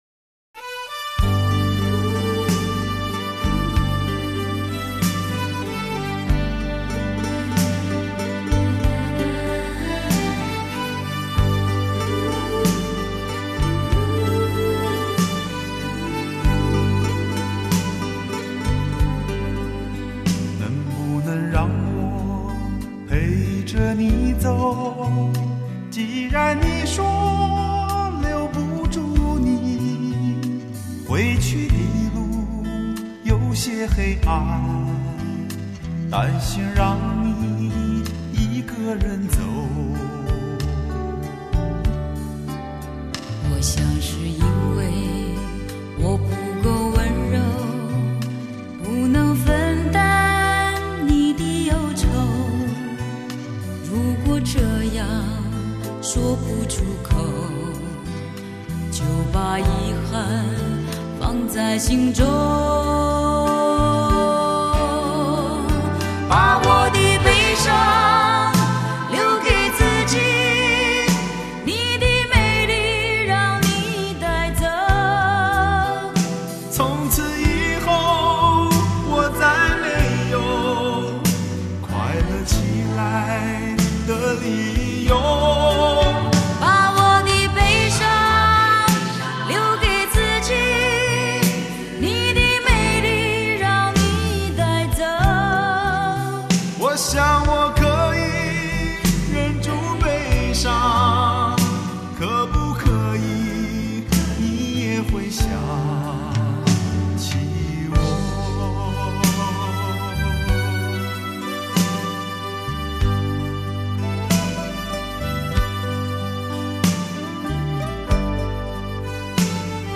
，深情款款，与歌曲中的无奈感伤，淋漓尽致，扣人心弦。